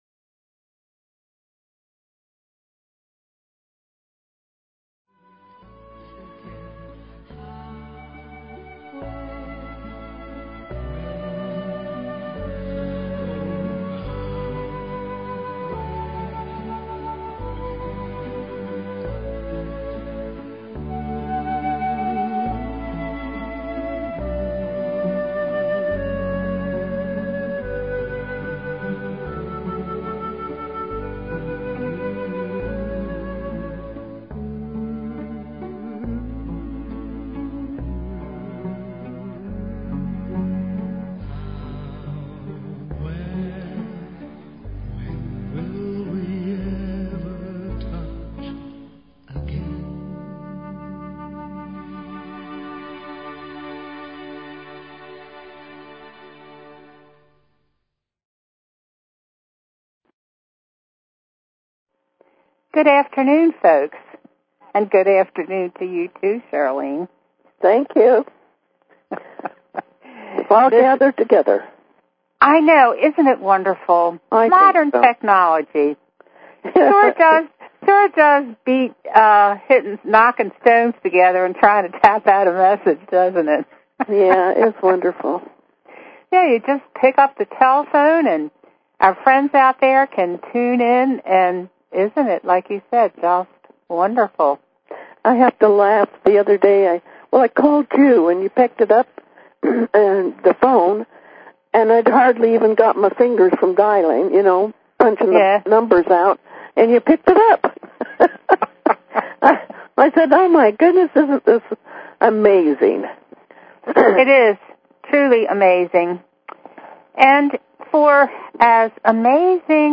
Talk Show Episode, Audio Podcast, The_Message and Courtesy of BBS Radio on , show guests , about , categorized as